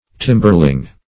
Timberling \Tim"ber*ling\, n.